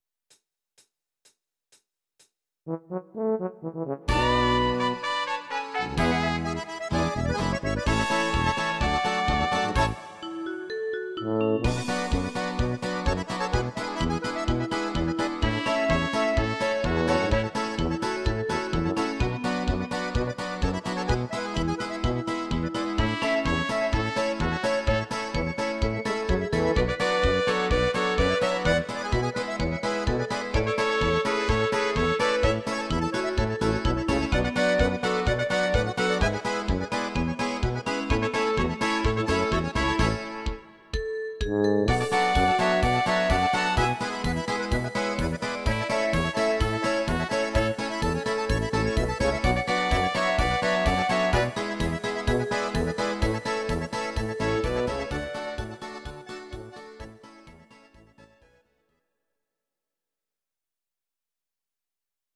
Oberkrainer Sound